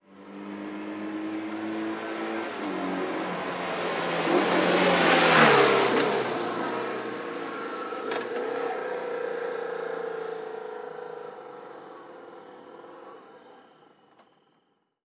Moto marca BMW pasando rápidamente 1
motocicleta
Sonidos: Transportes